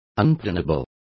Complete with pronunciation of the translation of unpardonable.